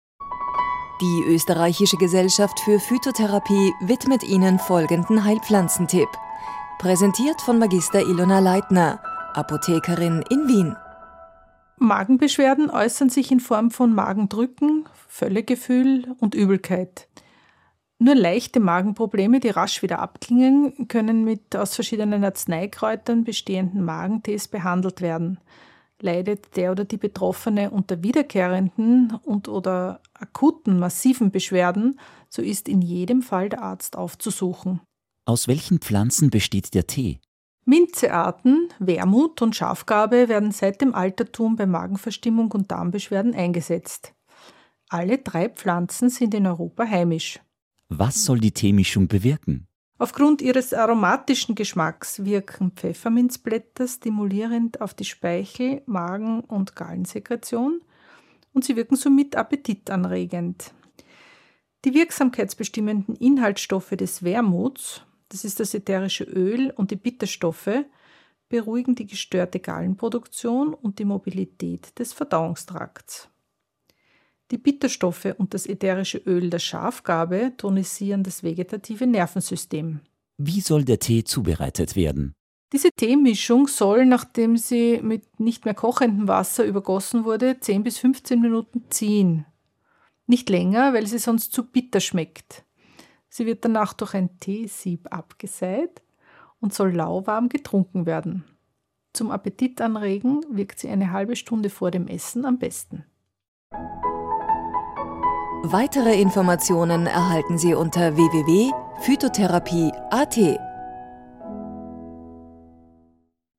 auf radio klassik Stephansdom.